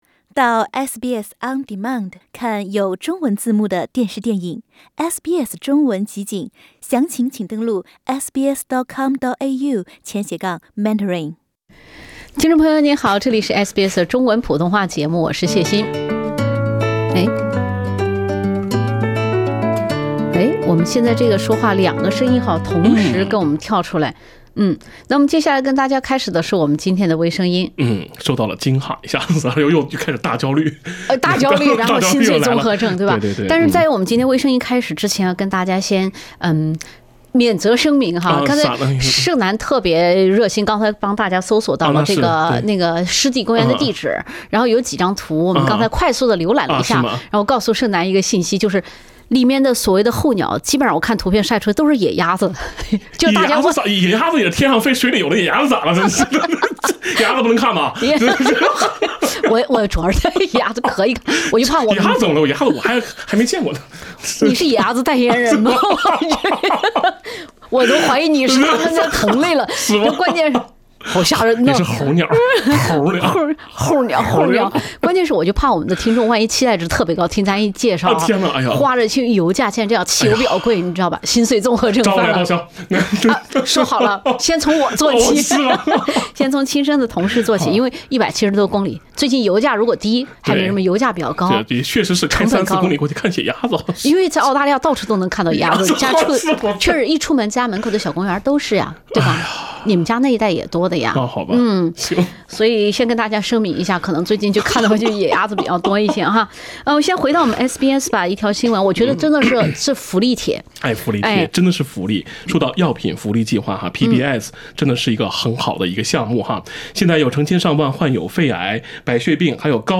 另类轻松的播报方式，深入浅出的辛辣点评，更劲爆的消息，更欢乐的笑点，敬请收听每周四上午8点30分播出的时政娱乐节目《微声音》。